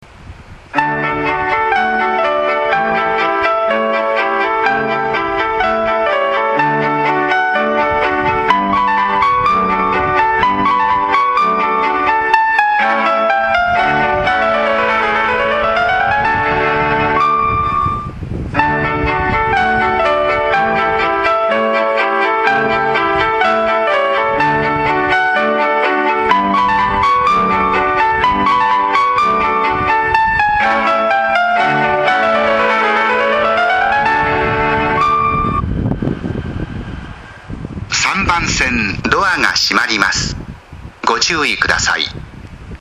発車メロディー  余韻切りです。